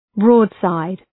{‘brɔ:dsaıd}